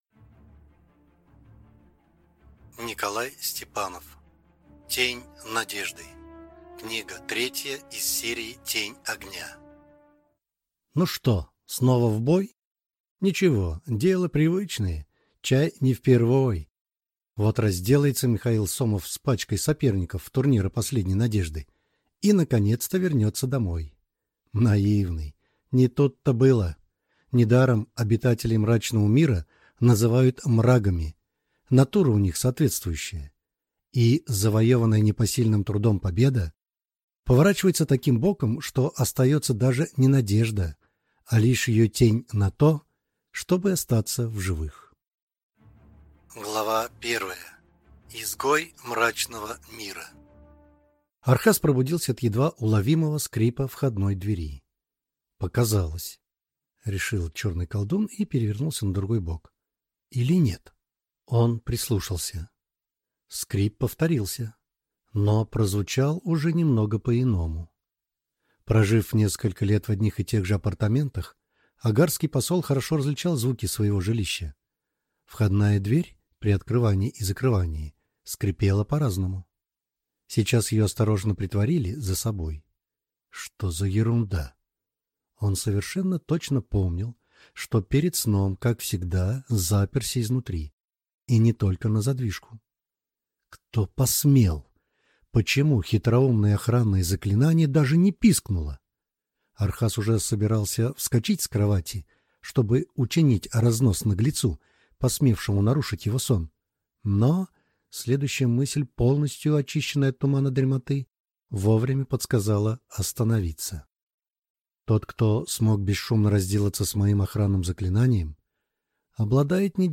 Аудиокнига Тень надежды | Библиотека аудиокниг
Прослушать и бесплатно скачать фрагмент аудиокниги